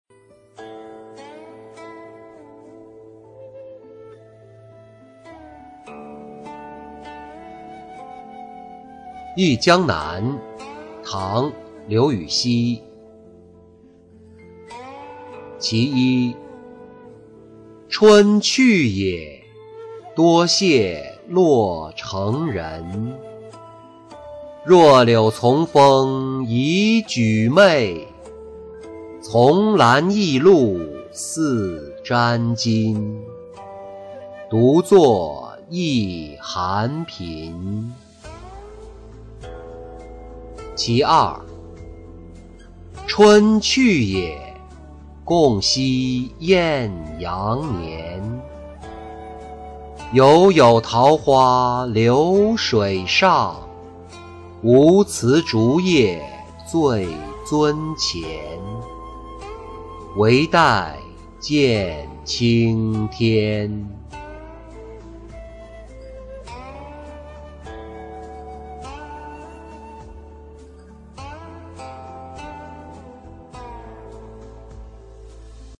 忆江南·春去也-音频朗读